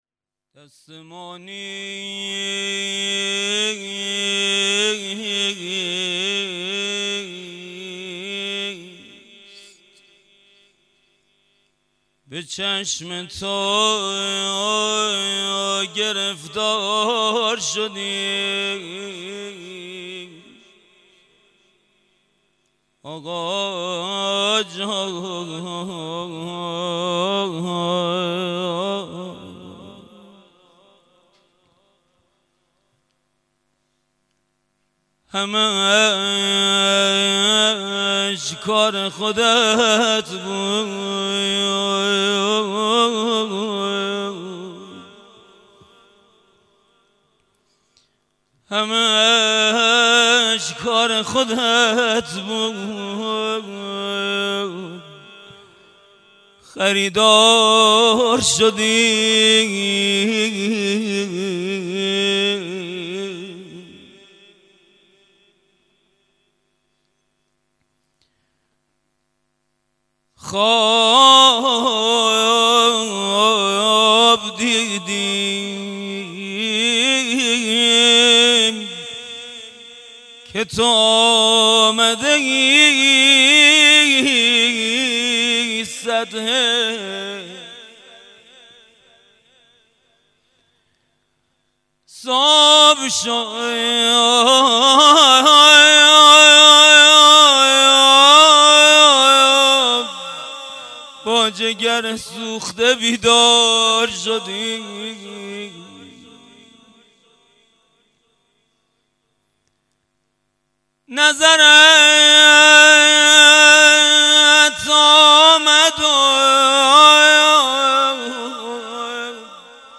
جلسه هفتگی روضه97/11/21